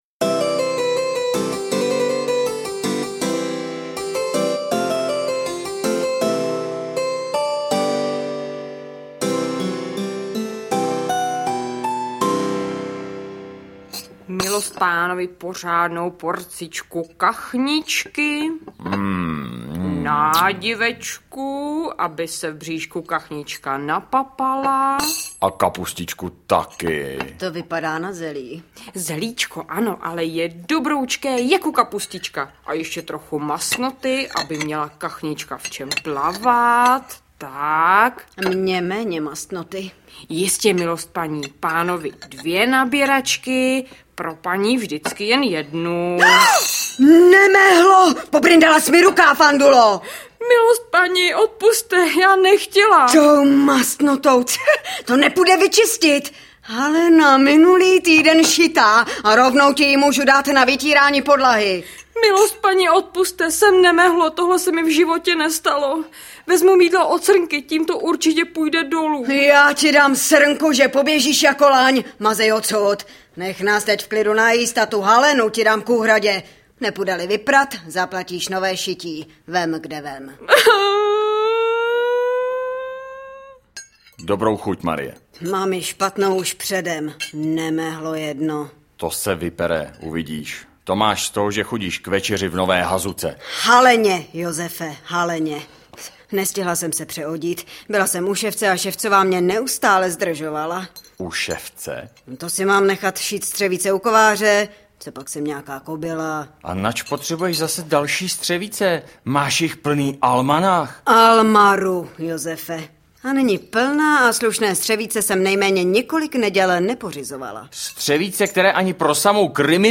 Obsahem druhého alba jsou 3 zpívané pověsti z Plzně v podání Démophobie a dále zdramatizované pověsti z Domažlic, Horšovského Týna, Kašperských Hor, Sušice a Kralovic v originálním...